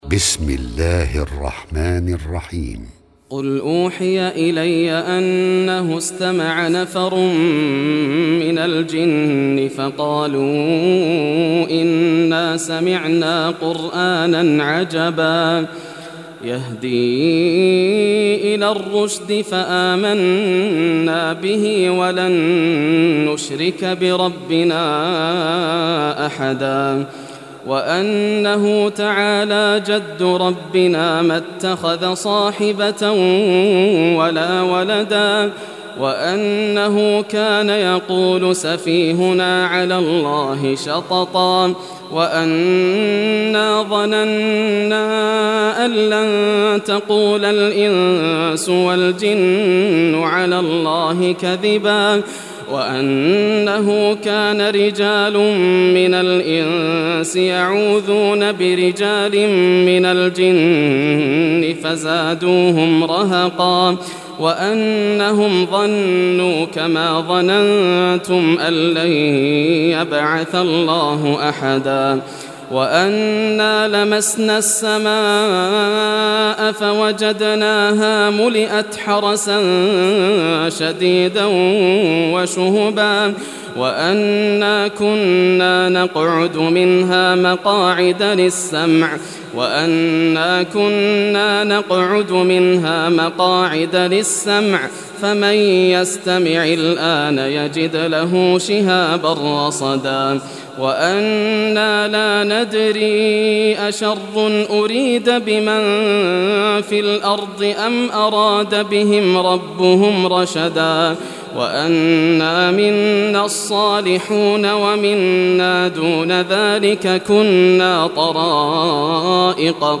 Surah Al-Jinn Recitation by Yasser al Dosari
Surah Al-Jinn, listen or play online mp3 tilawat / recitation in Arabic in the beautiful voice of Sheikh Yasser al Dosari.